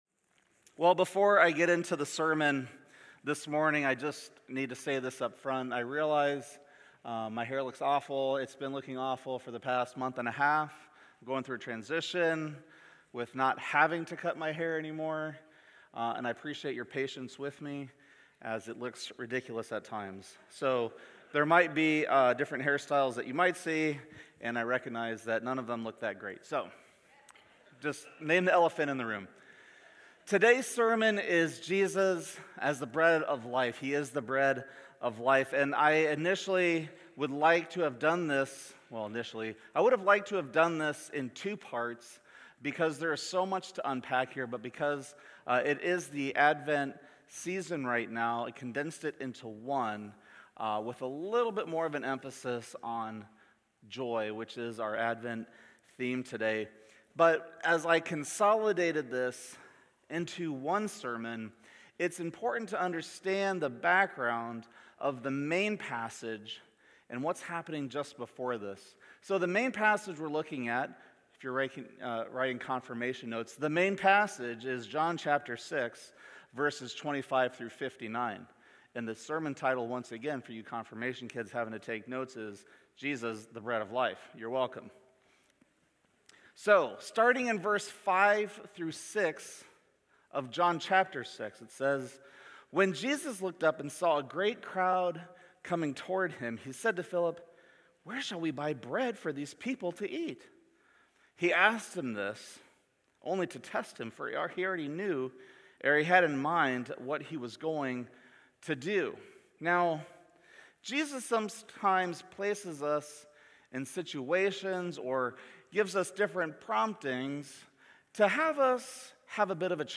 Sermons | Countryside Covenant Church